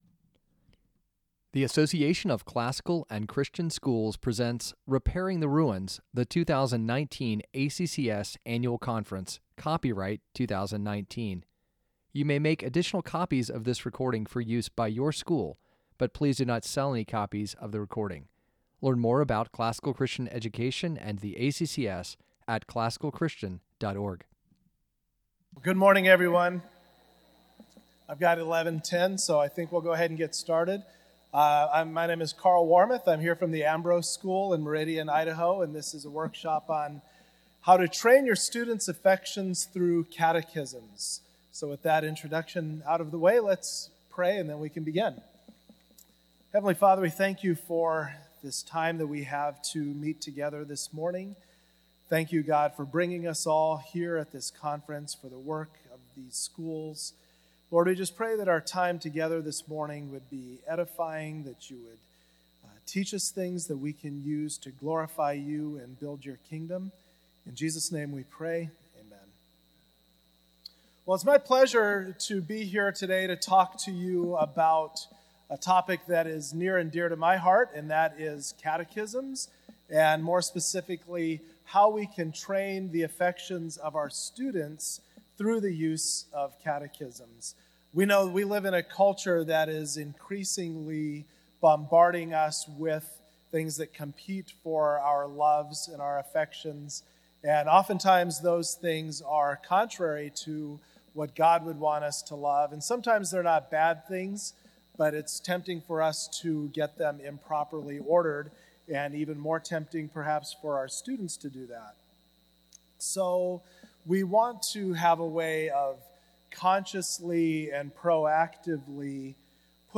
2019 Foundations Talk | 01:01:06 | K-6, General Classroom, Virtue, Character, Discipline
Jul 30, 2019 | Conference Talks, Foundations Talk, General Classroom, K-6, Library, Media_Audio, Virtue, Character, Discipline | 0 comments